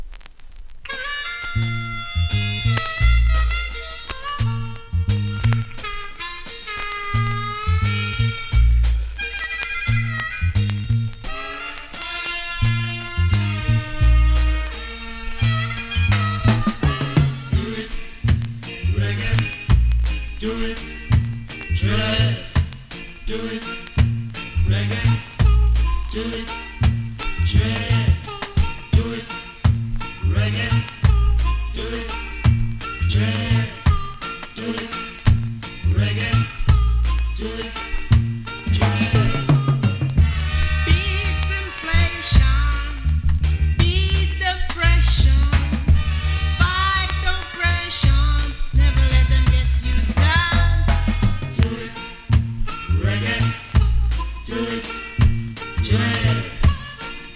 FUNKYレゲエ!!
スリキズ、ノイズ比較的少なめで